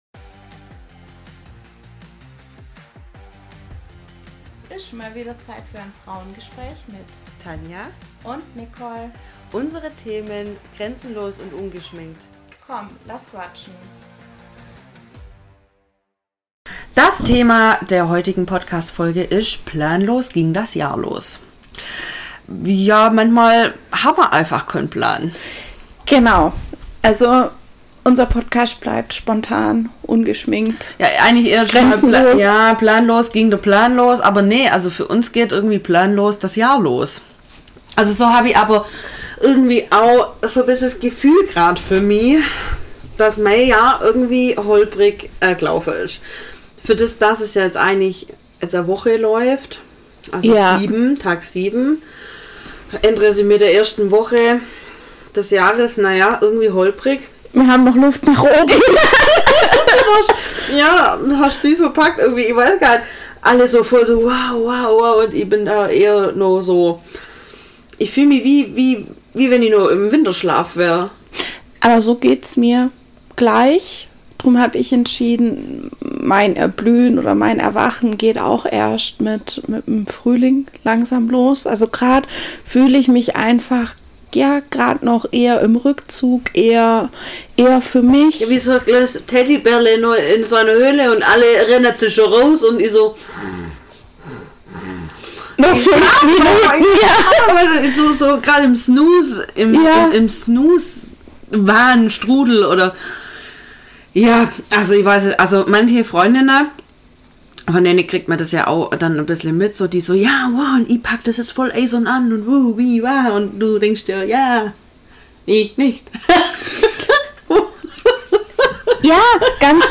#041 Planlos ging der Plan oder besser gesagt das Jahr los ~ Frauengespräche │ grenzenlos & ungeschminkt Podcast